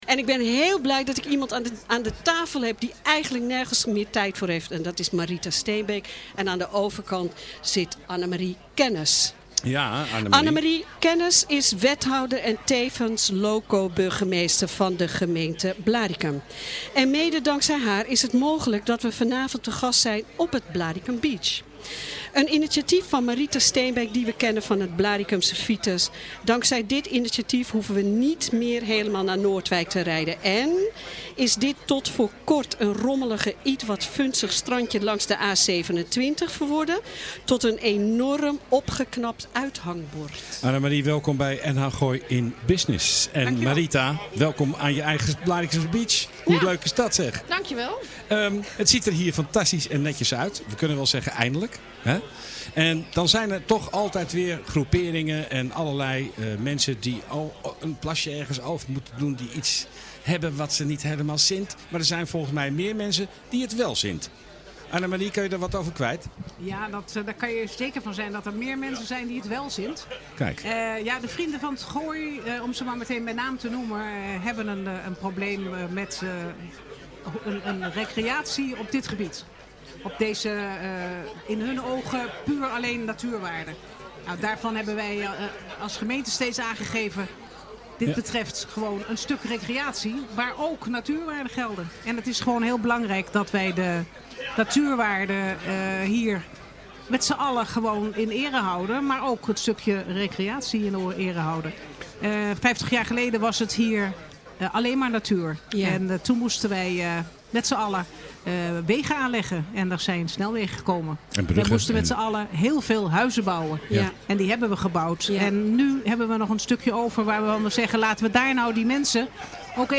Annemarie Kennis is wethouder en tevens loco burgermeester van de gemeente Blaricum. Mede dankzij haar is het mogelijk dat we vanavond te gast zijn op het Blaricum Beach.